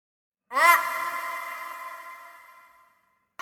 Ah Scream